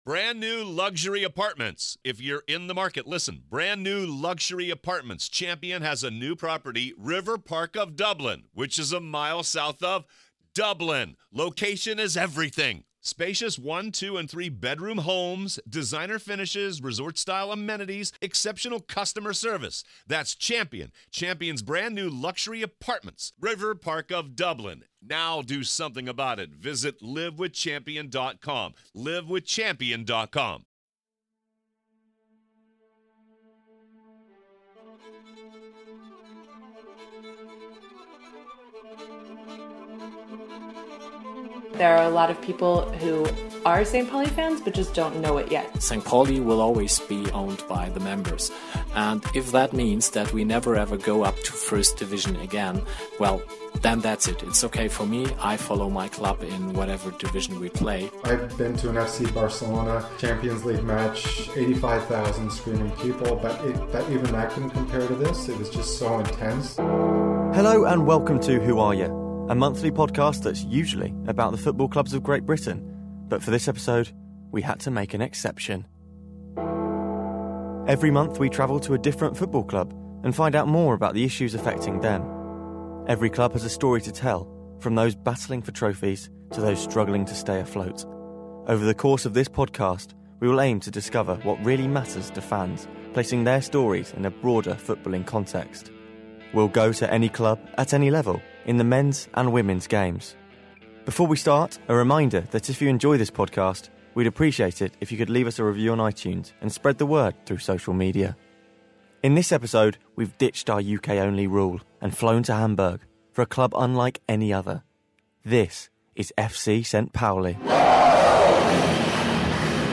We go to the Millerntor, and speak to St. Pauli fans from all around the world to ask them....Who Are Ya?